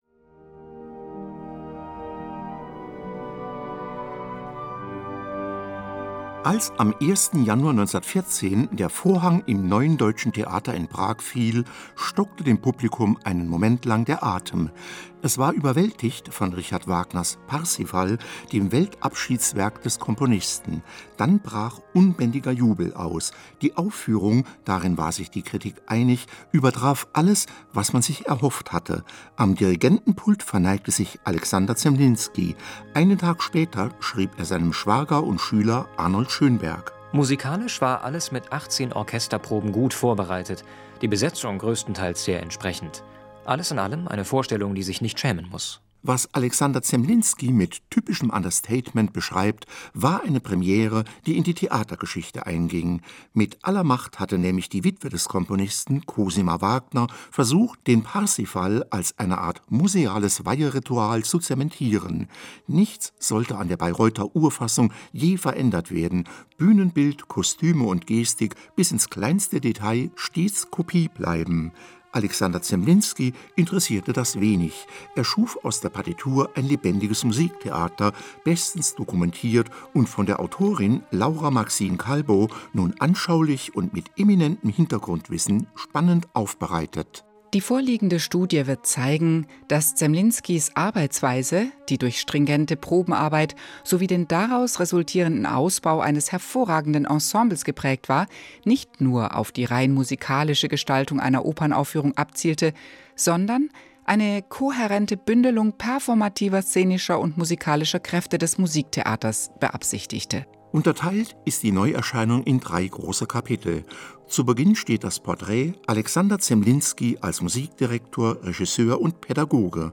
Buch-Tipp